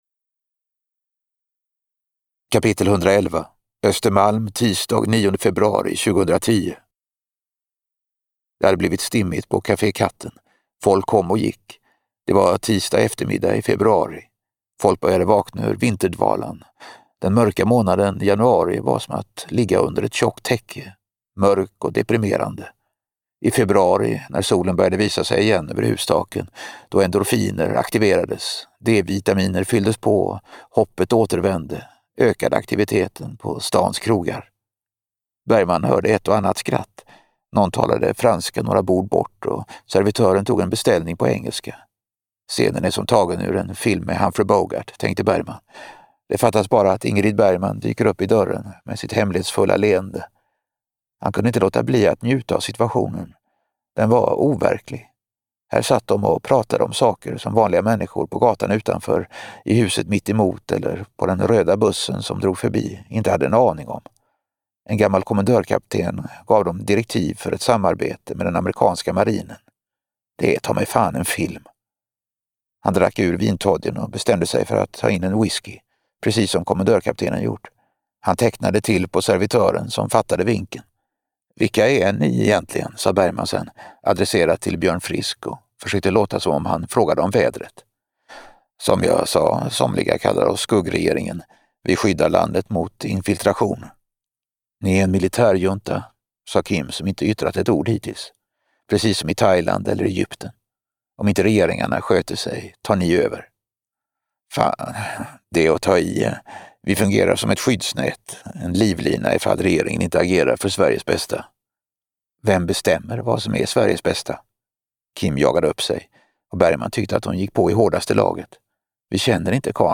Lyssna på ett kapitel ur Natoagenten som berör ämnet skuggregering. Läsare Reine Brynolfsson: